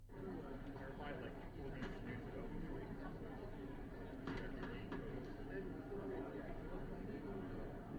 ハイブリッド型アクティブノイズキャンセリング
ハイブリッド型 ANC: オフ
オフの状態でも遮音性が高いですが、ANCをオンにすることで大幅にカットできます。
razer-blackshark-v3-pro-hybrid-anc-off.wav